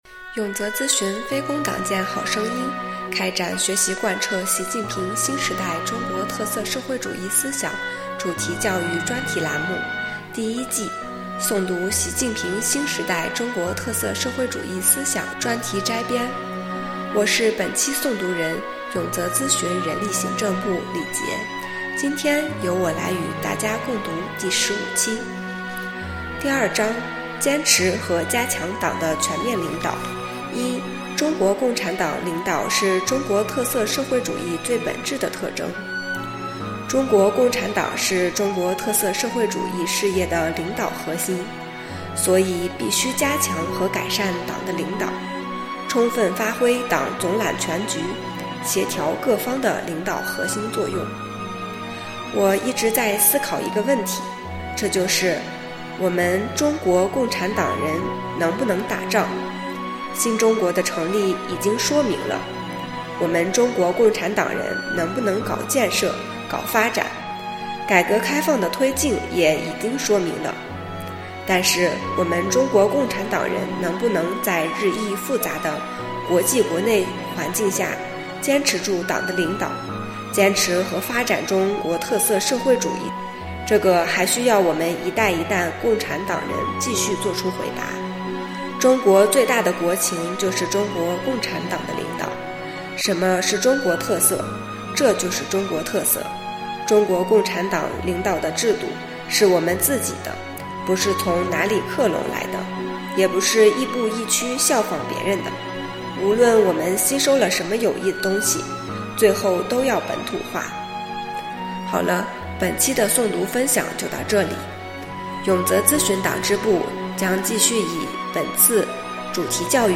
【诵读】《习近平新时代中国特色社会主义思想专题摘编》第15期-永泽党建